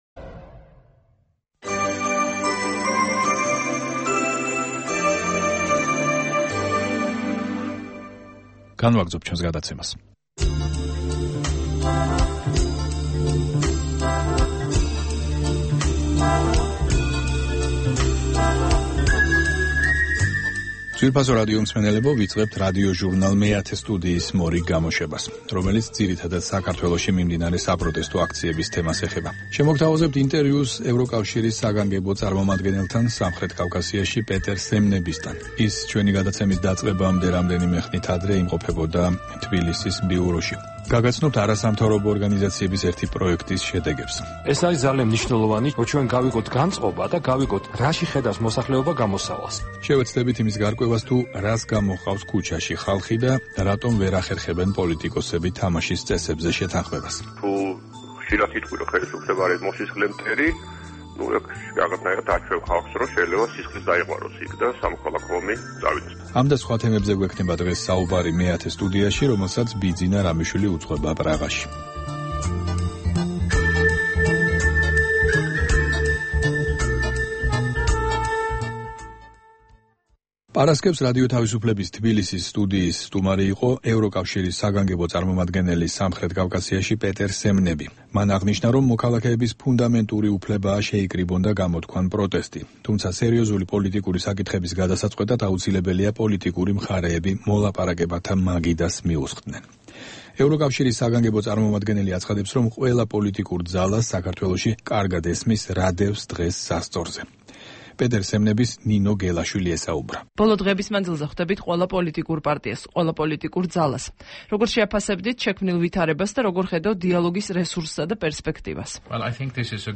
ადიოჟურნალ "მეათე სტუდიის" მორიგი გამოშვება ძირითადად საქართველოში მიმდინარე საპროტესტო აქციების თემას ეხება. შემოგთავაზებთ ინტერვიუს ევროკავშირის საგანგებო წარმომადგენელთან სამხრეთ კავკასიაში პეტერ სემნებისთან, რომელიც 17 აპრილს ჩვენი რედაქციის სტუმარი გახლდათ; გაგაცნობთ არასამთავრობო ორგანიზაციების ერთი პროექტის შედეგებს; შევეცდებით იმის გარკვევას, თუ რას გამოჰყავს ქუჩაში ხალხი და რატომ ვერ ახერხებენ პოლიტიკოსები თამაშის წესებზე შეთანხმებას; ვისაუბრებთ თეატრისა და ურბანული კულტურის შესახებ.